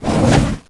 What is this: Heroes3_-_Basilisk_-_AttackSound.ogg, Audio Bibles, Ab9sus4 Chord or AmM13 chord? Heroes3_-_Basilisk_-_AttackSound.ogg